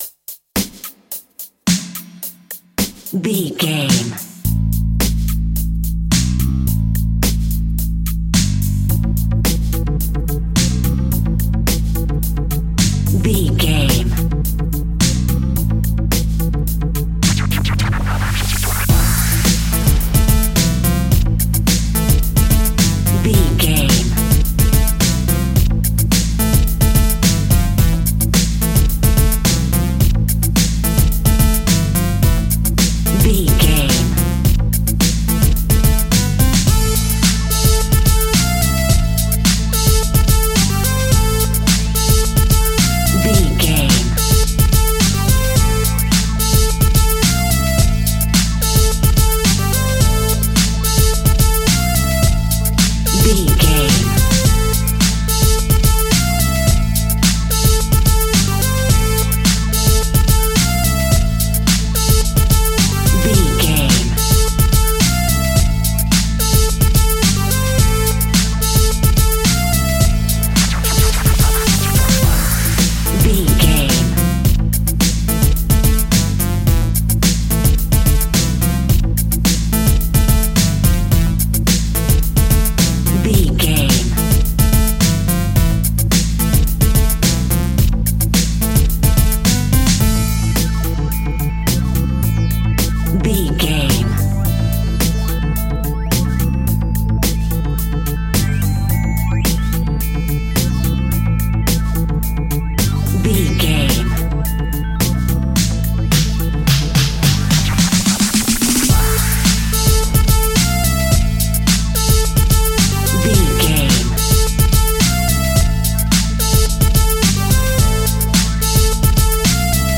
Aeolian/Minor
hip hop instrumentals
downtempo
synth lead
synth bass
synth drums
turntables